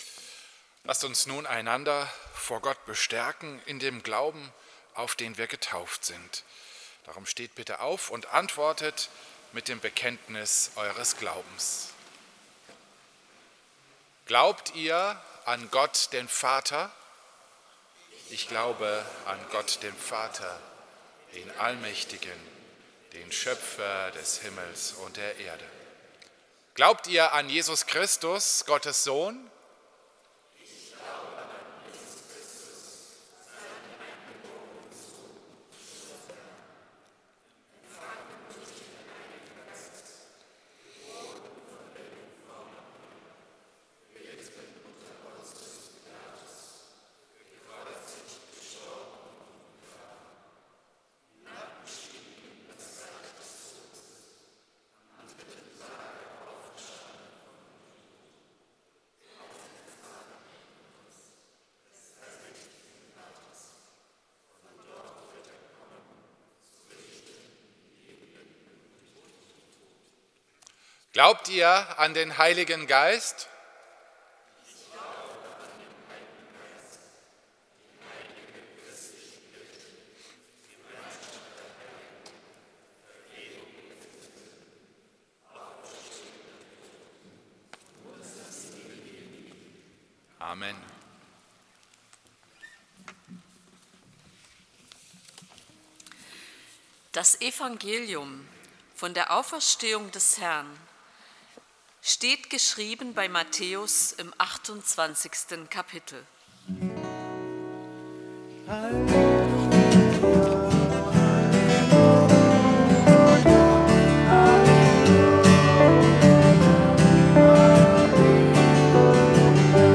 Predigt zur Osternacht